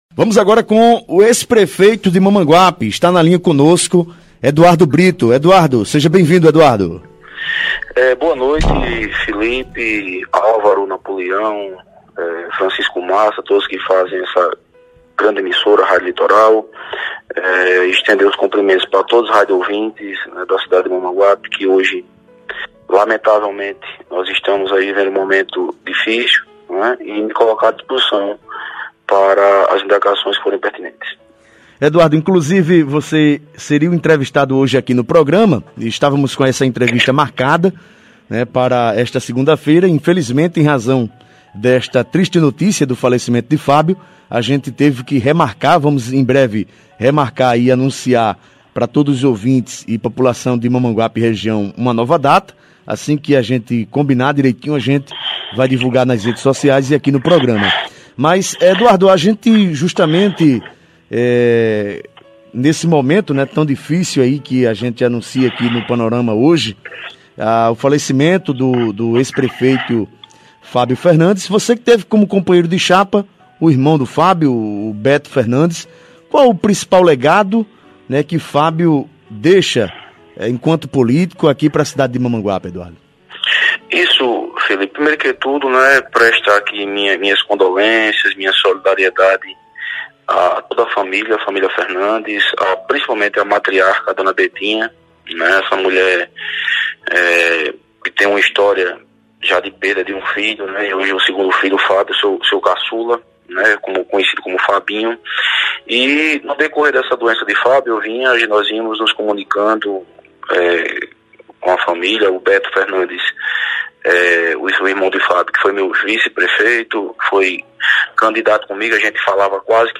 Durante participação por telefone no Programa ‘Panorama 104’, da rádio Litoral Norte, Brito revelou surpresa e ao mesmo tempo tristeza com a morte do político.